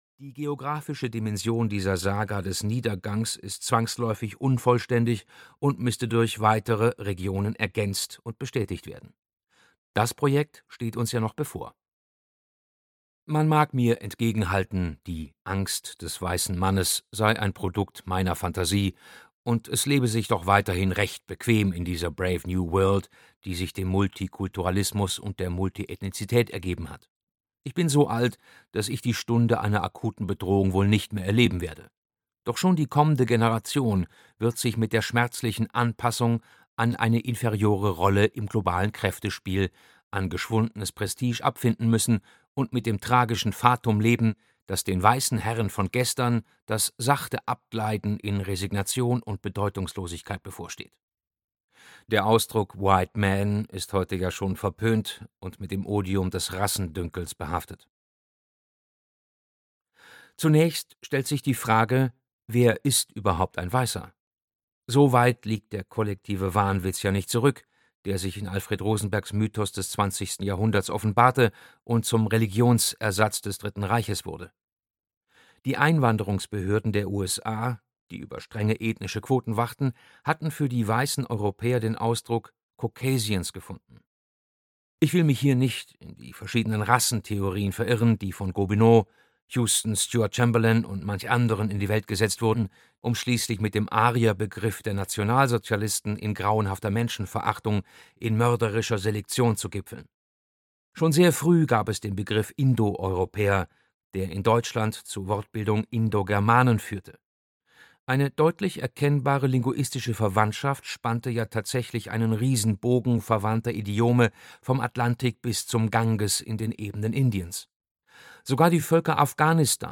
Die Angst des weißen Mannes - Peter Scholl-Latour - Hörbuch